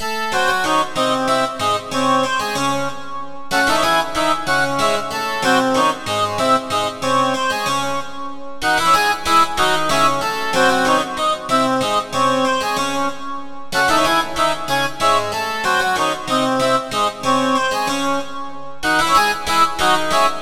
Ridin_ Dubs - Harpsicord.wav